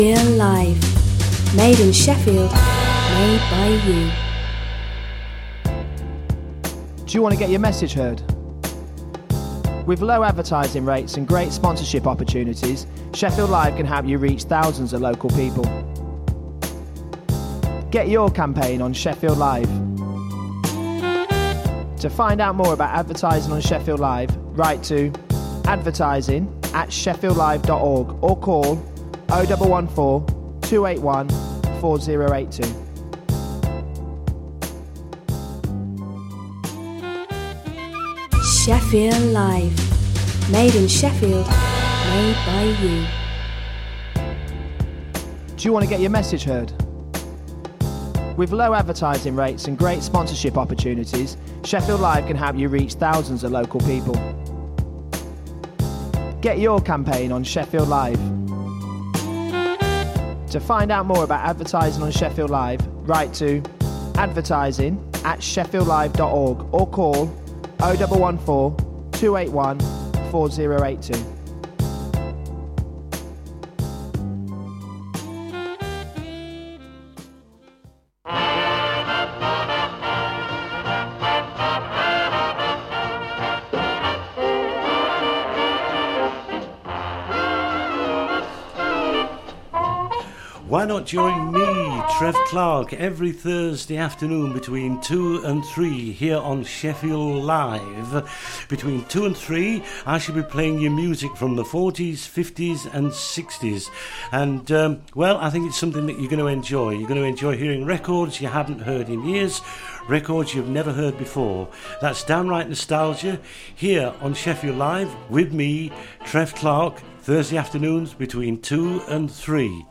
Shefffield Live presents… One hour of the best and the rarest in blues and rhythm ‘n’ blues